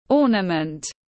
Đồ trang trí cây thông Nô-en tiếng anh gọi là ornament, phiên âm tiếng anh đọc là /ˈɔː.nə.mənt/